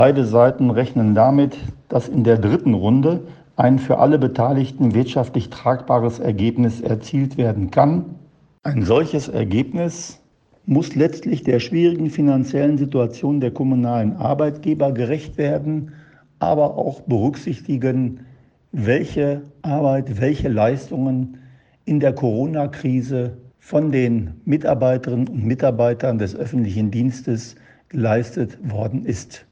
Doch die Arbeitgeberseite hat bei den laufenden Tarifverhandlungen noch kein Angebot gemacht. Emsdettens Bürgermeister Georg Moenikes, der auch Sprecher der Münsterland-Kommunen ist, fordert von den Tarifparteien, die wirscthaftliche Krise bei den Verhandlungen im Auge zu behalten.